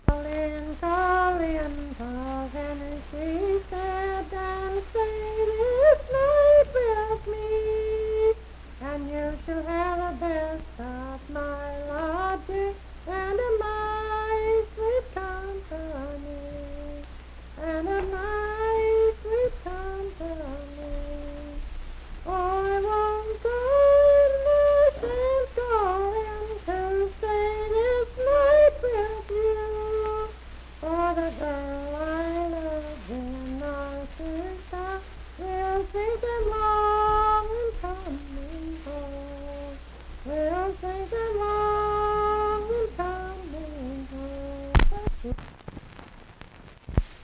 Unaccompanied vocal music
in Uffington, W.V..
Verse-refrain 2(4).
Voice (sung)